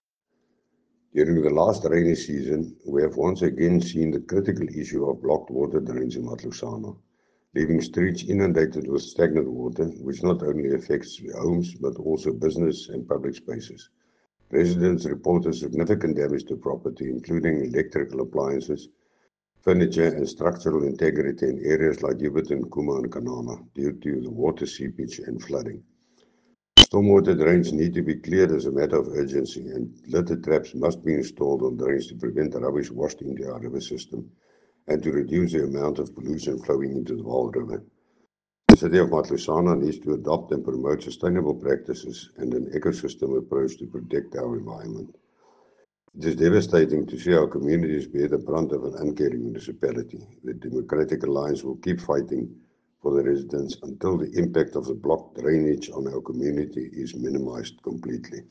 Issued by Gerhard Strydom – DA Councillor, City of Matlosana Local Municipality
Note to broadcasters: Please find linked soundbites in